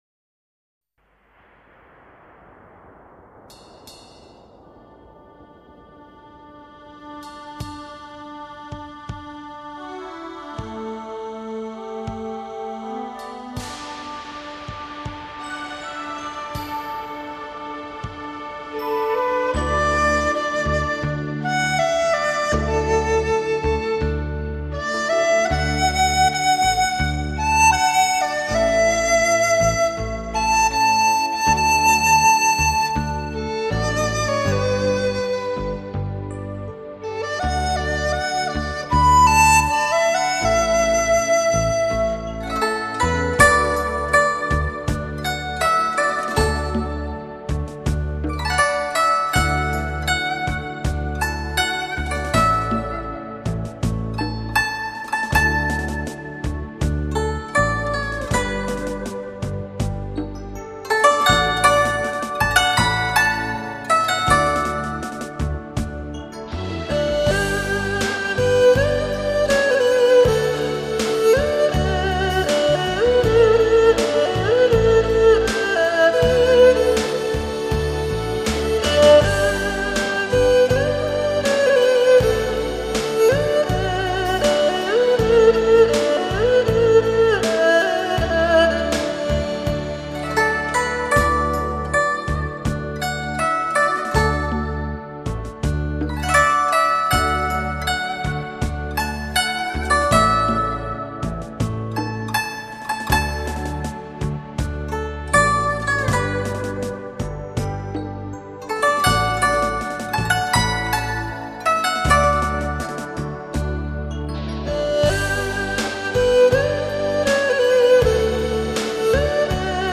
此系列编曲方面比较柔和一些，适合闲情
时候欣赏的民乐器轻音乐。